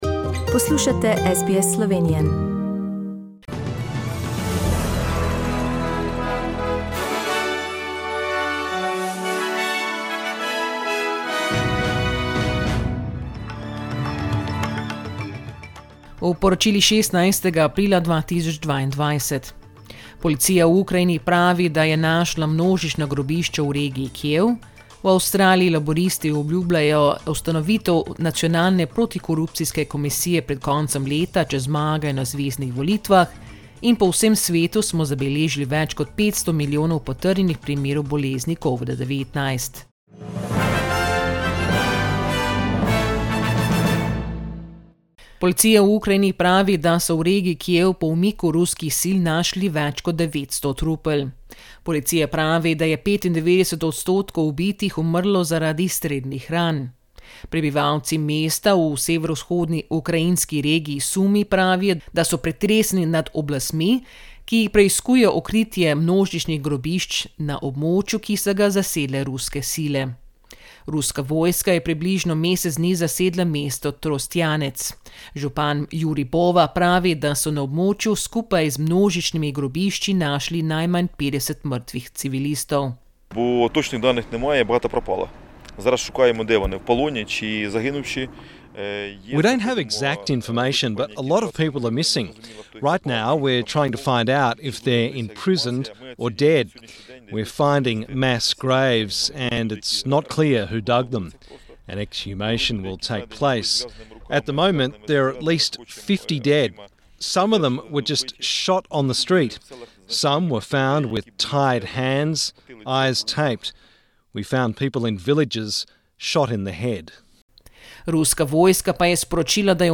Poročila Radia SBS v slovenščini 16.aprila